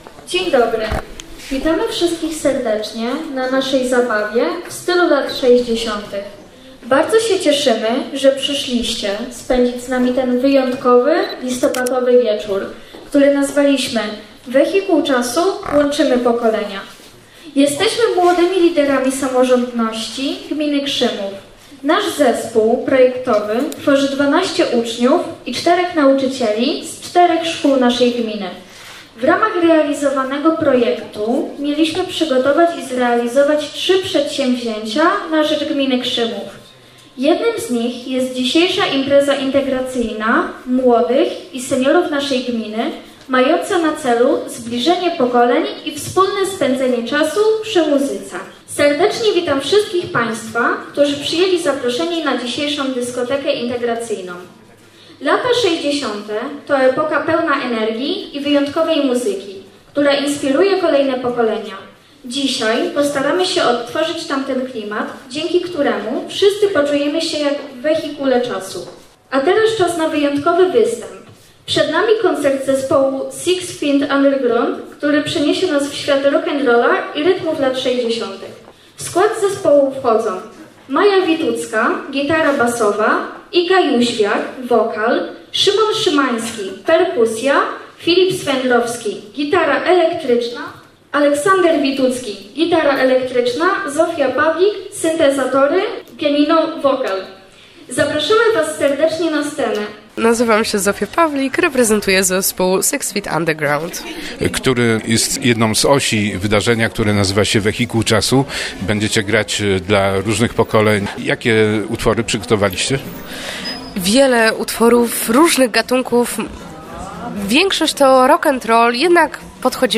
Zabawa przy dobrej muzyce, również wykonywanej na żywo, wypełniła czas wydarzenia zatytułowanego „Wehikuł czasu – łączymy pokolenia”, przygotowanego w ramach projektu „Młodzi Liderzy Samorządności Gminy Krzymów.”